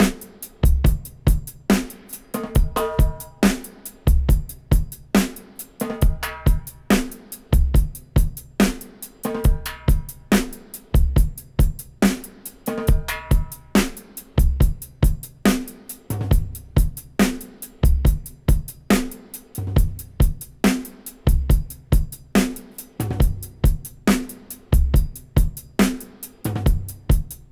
70-DRY-02.wav